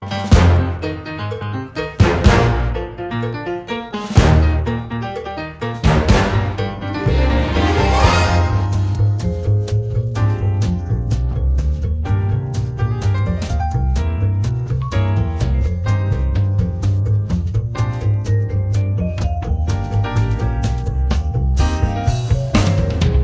tmpd6_mx2pjday-of-sun-clip_instrum2.wav